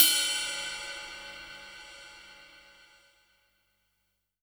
• Drum Ride Sound G Key 10.wav
Royality free ride sound tuned to the G note. Loudest frequency: 8015Hz
drum-ride-sound-g-key-10-jjG.wav